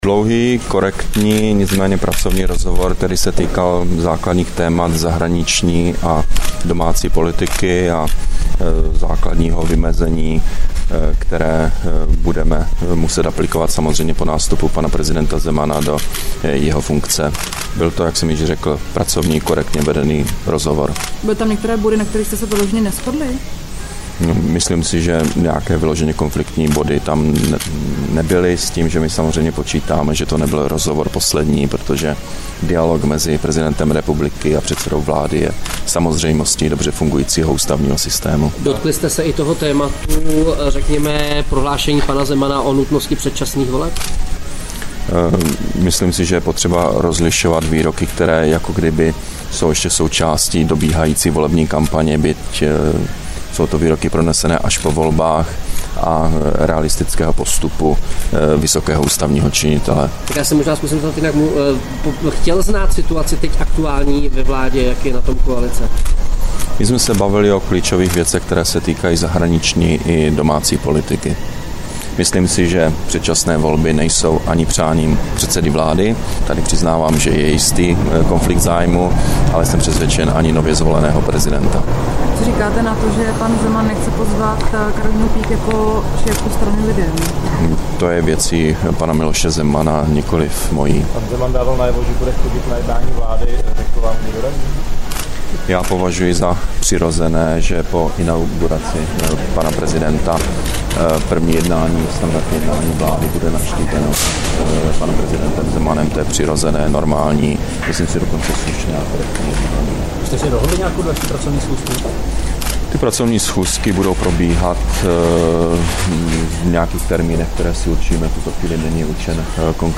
Vyjádření premiéra Nečase po schůzce s novým prezidentem, 5. února 2013